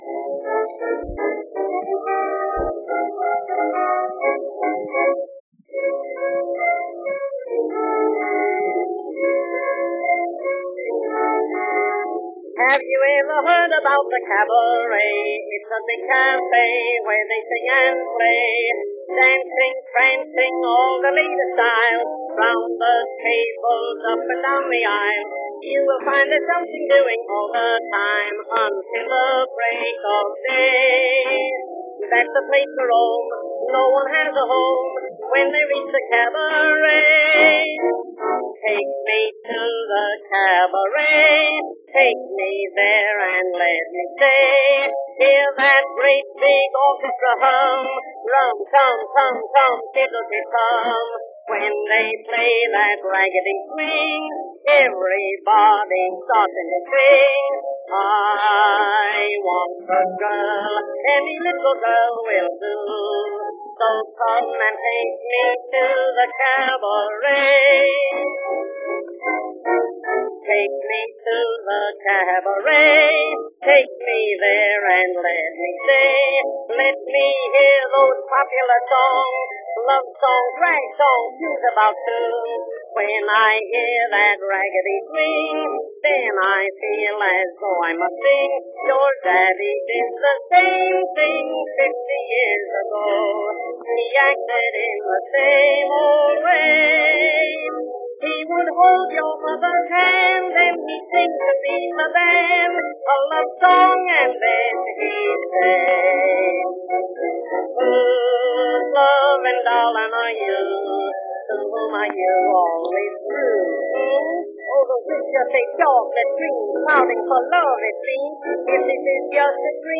Two classic ragtime tunes originally recorded in 1912.